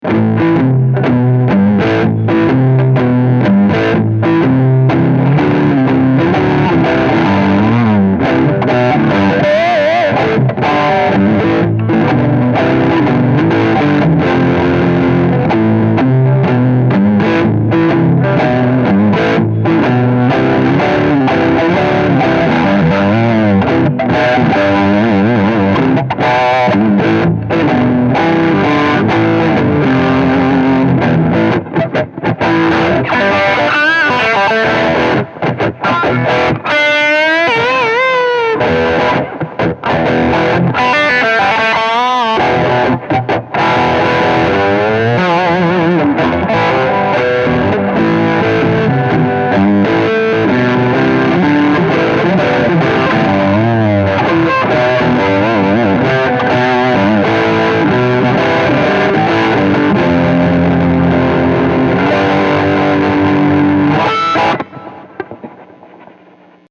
The guitar has an amazing sound, with great clarity in the lower + upper registers, with excellent sustain and nice, low action.
The effects are stock Logic presets, and the clean sounds are straight signal from the ALD 600 pre with no EQ or compression.